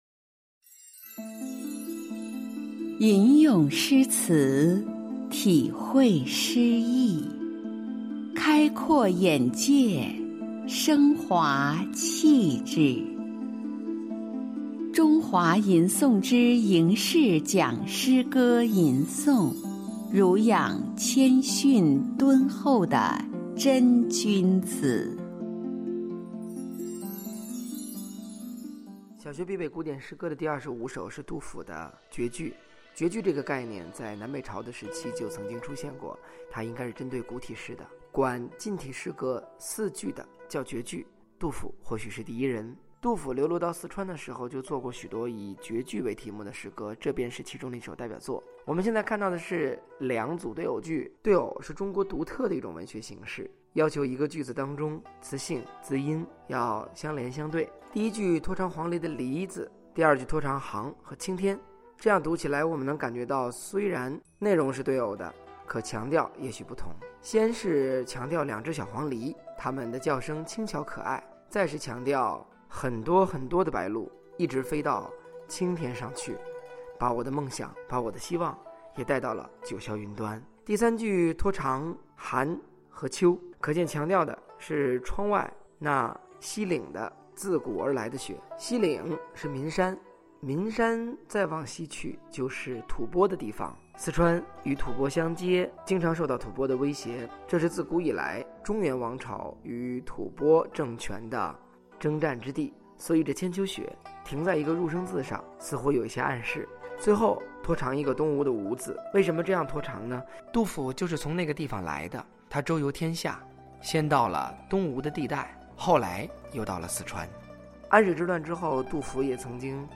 吟诵书写杜甫《绝句》（两个黄鹂鸣翠柳）